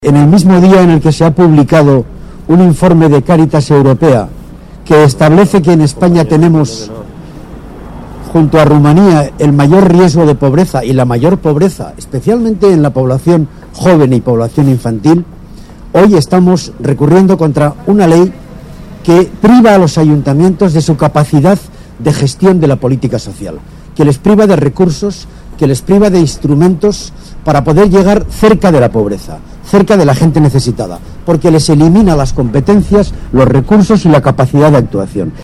Declaraciones de Ramón Jáuregui tras presentar el recurso en el Tribunal Constitucional contra la reforma local 28/03/2014